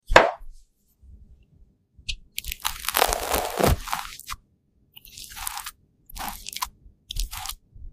Bunny Toast Spread Ai Asmr Sound Effects Free Download